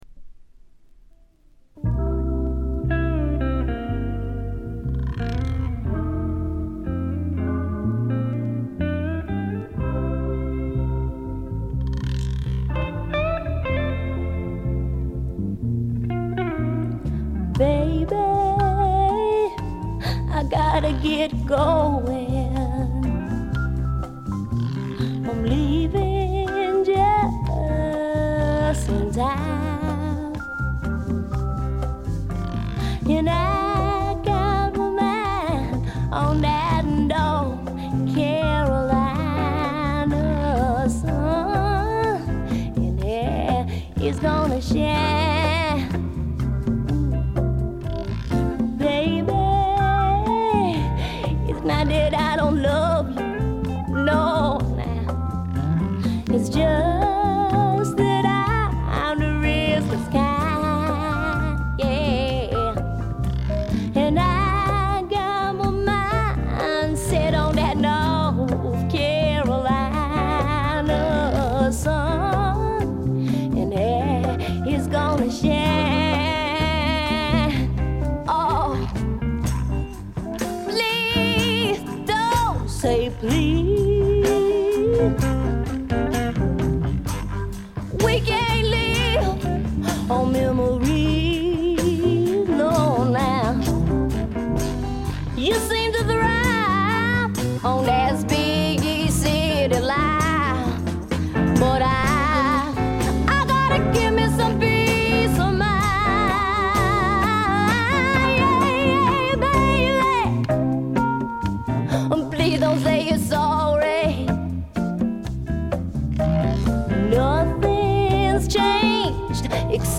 ホーム > レコード：米国 女性SSW / フォーク
ごくわずかなノイズ感のみ。
試聴曲は現品からの取り込み音源です。